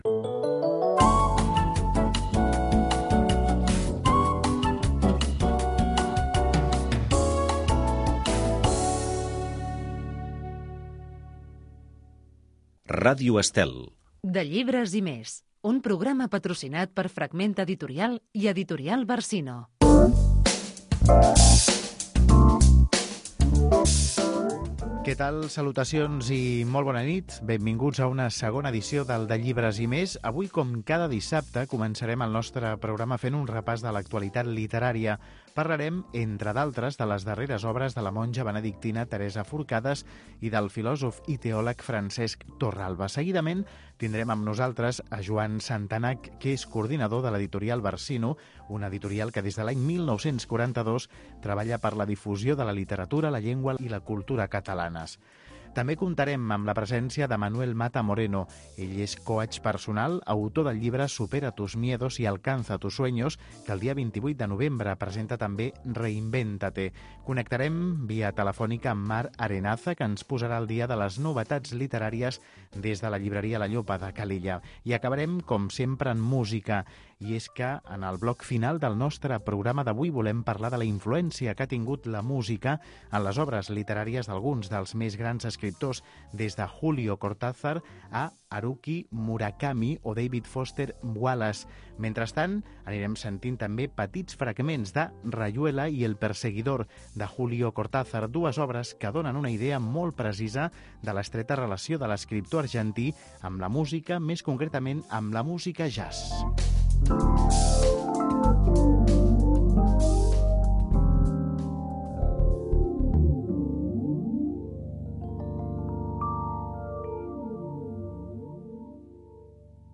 Indicatiu de l'emissora, presentació del programa amb el sumari, lectura d'un fragment literari de Julio Cortazar, indicatiu del programa, actualitat literària